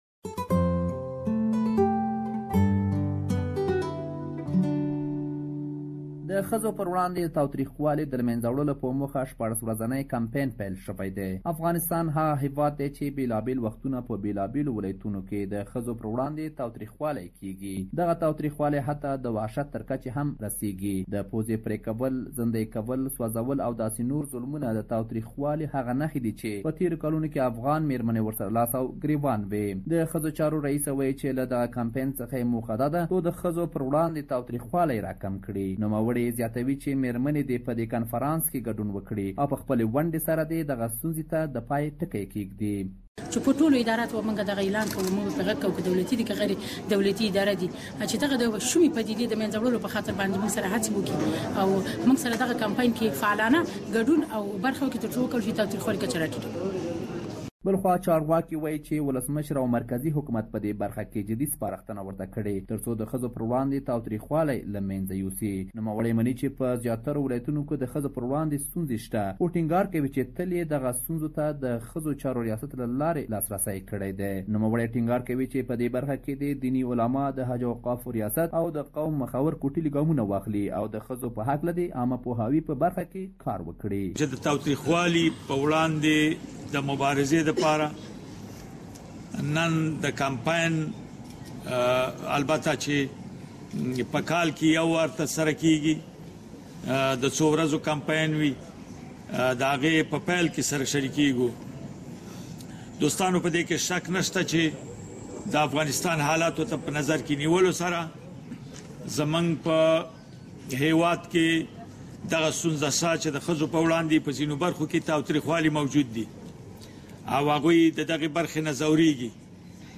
In Afghanistan's Nengrahar province a 16 days campaign has kicked off with the aim of spreading awareness of women's rights issues. Authorities say that president Ashraf Ghani is serious about putting an end to violence against women. We have a detailed report that you can listen to here.